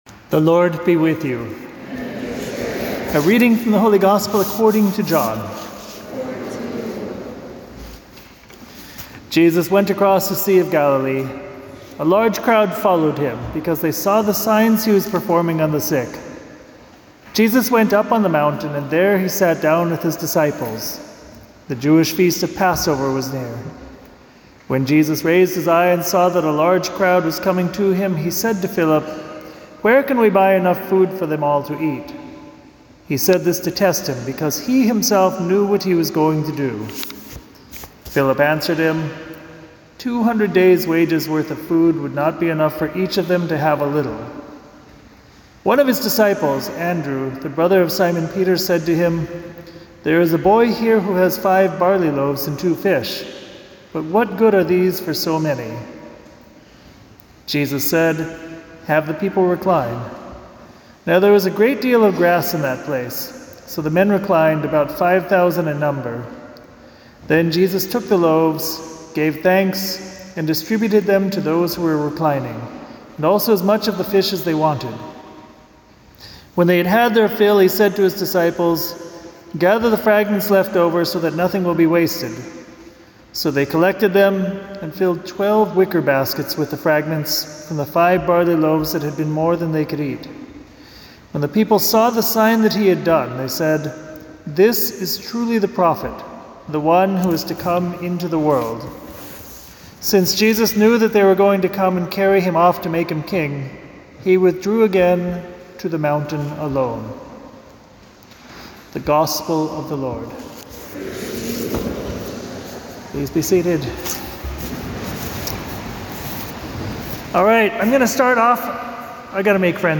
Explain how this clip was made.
April 12th at Church of the Resurrection in Rye, NY for the children and families of Resurrection Grammar School.